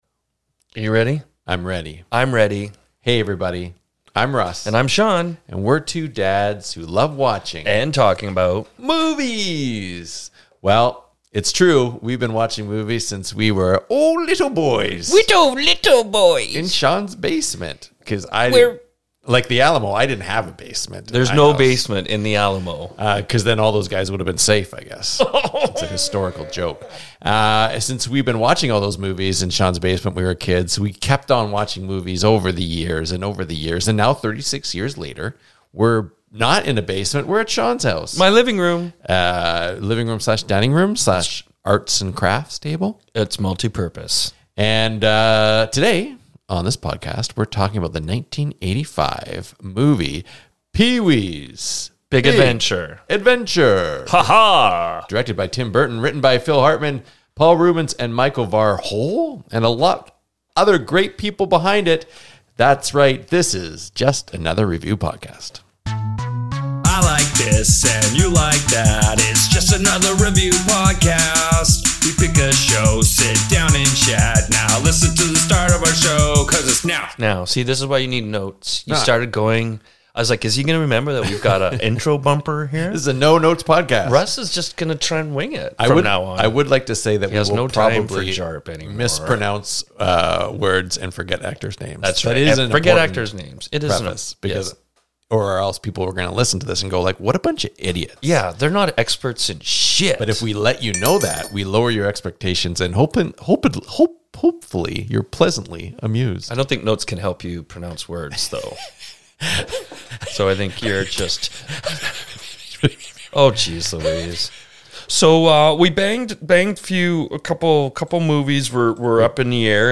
The two Biker Riders mispronounce words, forget actors names, and most importantly SCREAM REALLY LOUD! This is Just Another Review Podcast (or JARP for short).